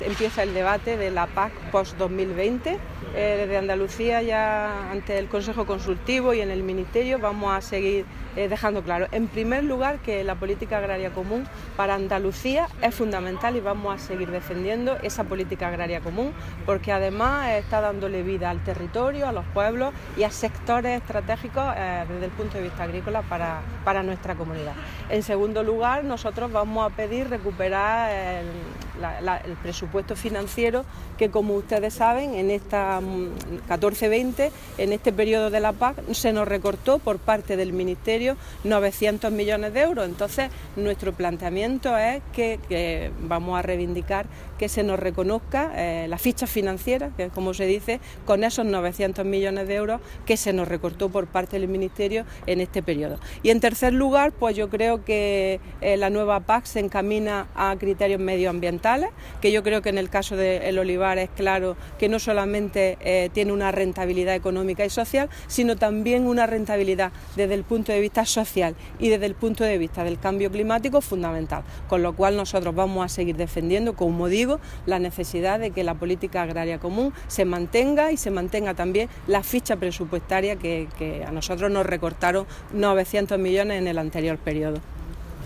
Declaraciones consejera Xylella